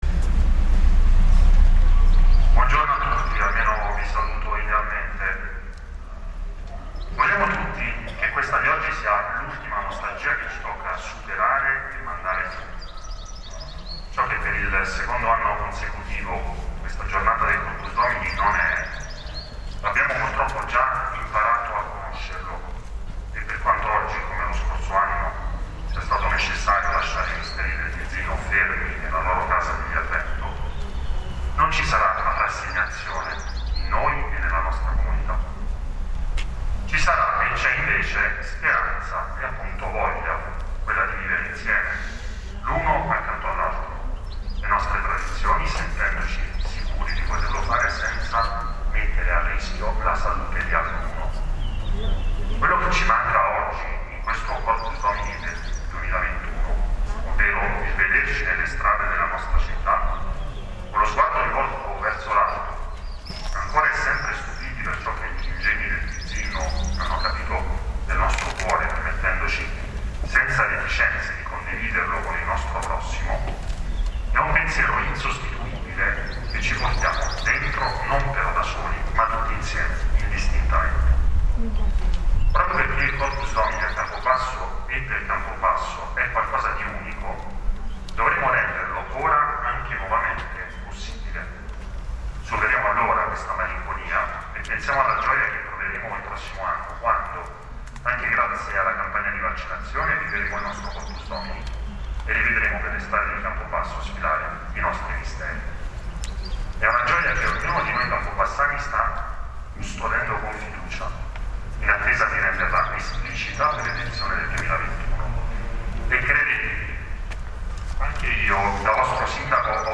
Durante la funzione religiosa,
Discorso-Del-Sindaco-Roberto-Gravina.mp3